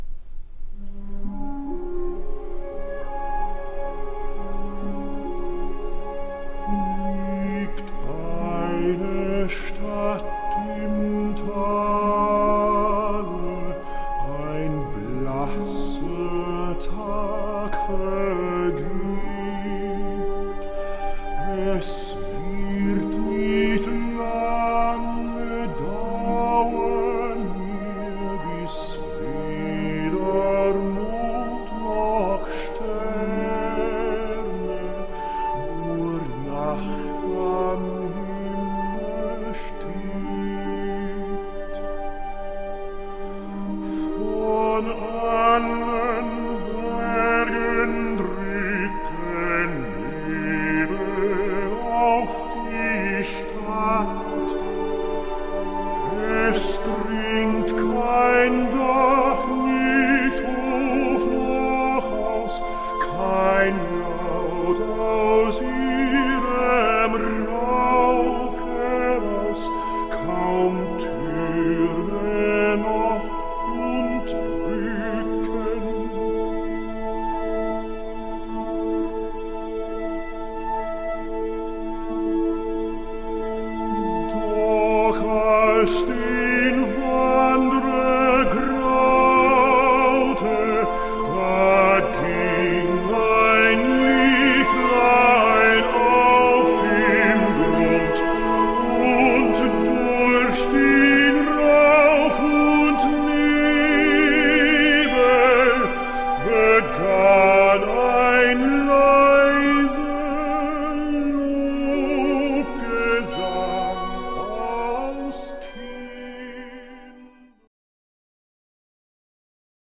Écouter une mélodie de Sibeliius orchestrée par E. Rautavaara et chantée par Gérald Finley, durée environ 2'